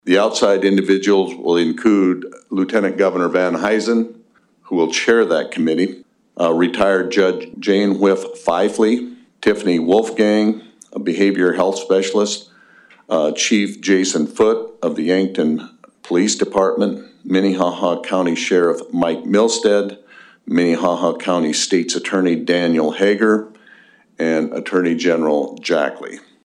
During a press conference on Thursday morning, South Dakota Governor Larry Rhoden announced “Project Prison Reset.”